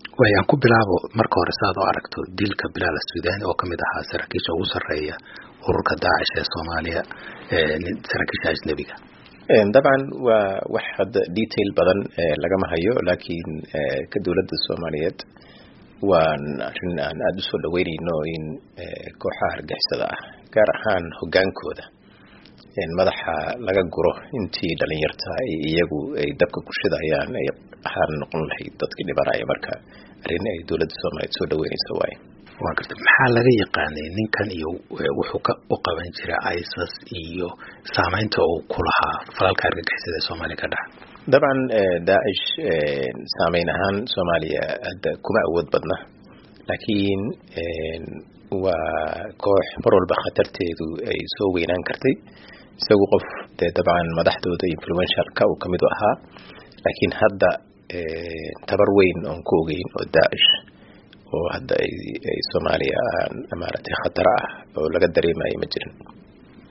ka waraystay Xuseen Sheekh Cali, La-taliyaha Amniga Qaranka ee dawladda Soomaaliya oo uu kula kulmay magaaladan Washington DC.